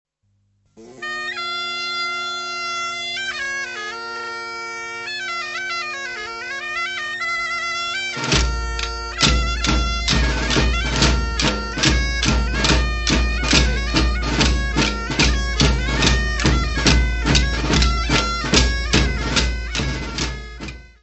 Folclore português : Trás-os-Montes e Alto Douro
Grupo Folclórico Mirandês de Duas Igrejas